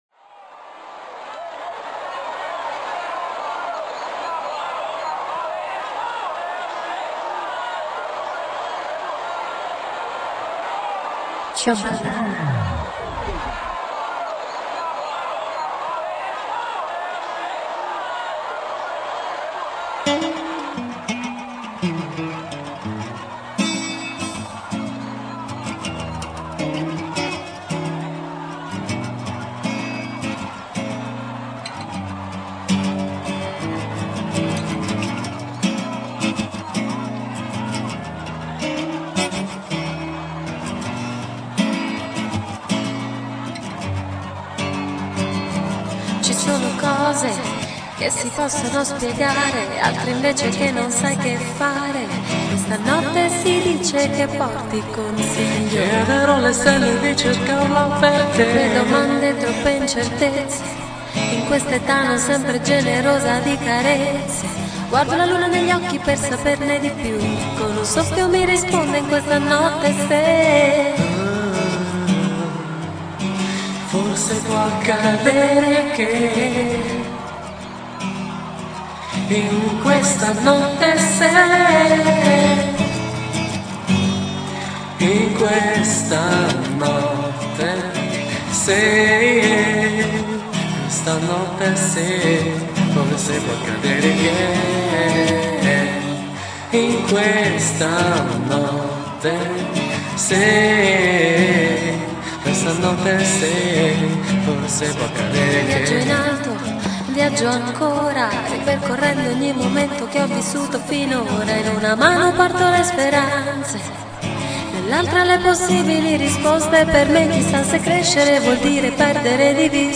Live RMX 1,9 kbyte